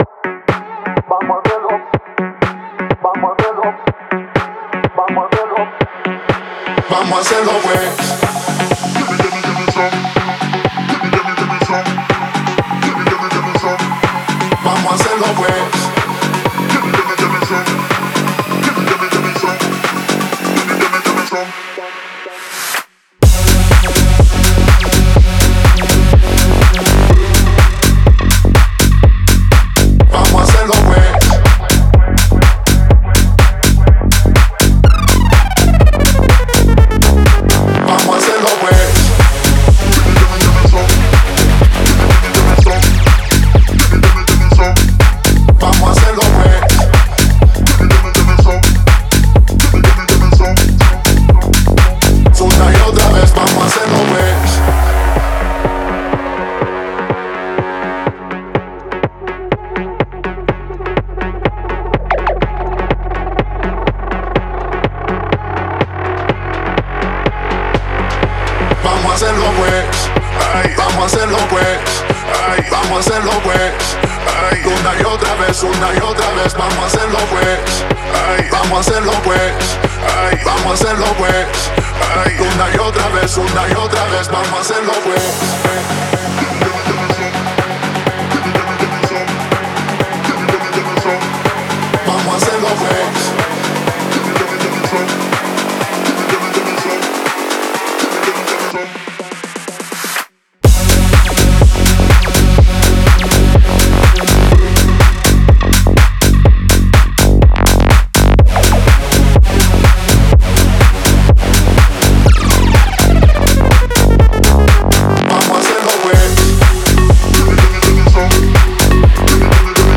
это зажигательный трек в жанре реггетон и EDM